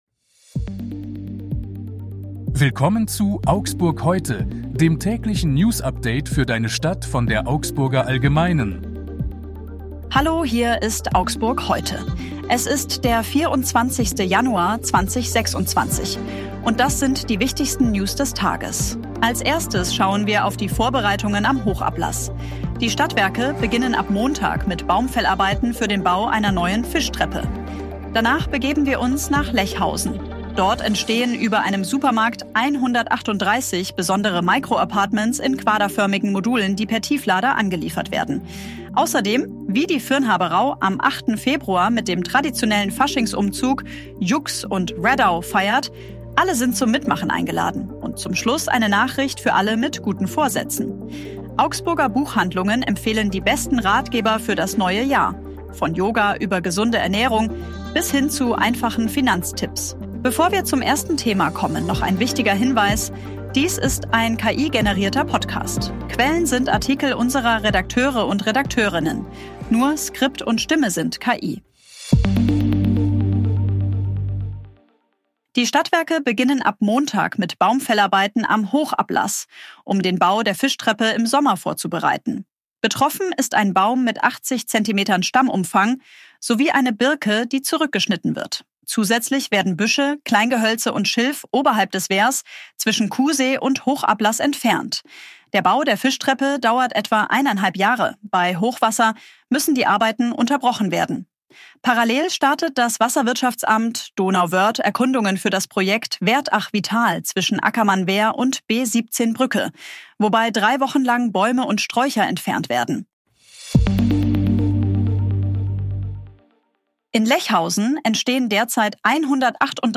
Hier ist das tägliche Newsupdate für deine Stadt.
Nur Skript und Stimme sind